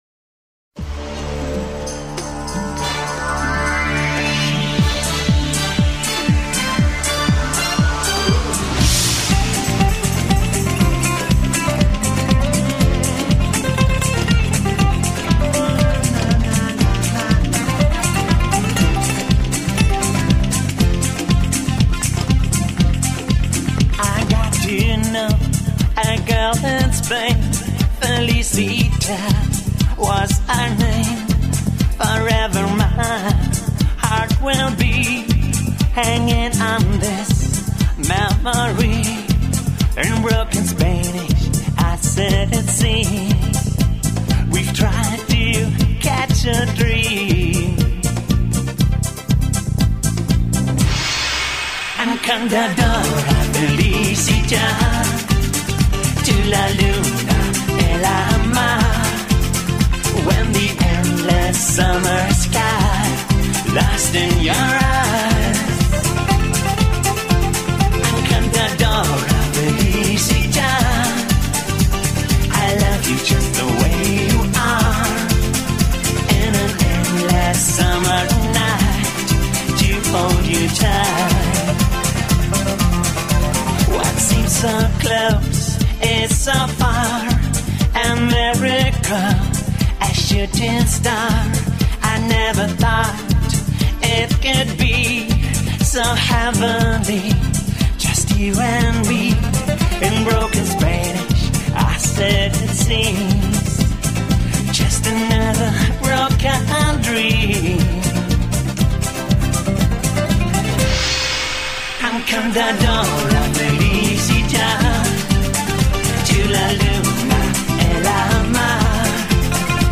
Жанр: Pop/Disco Альбом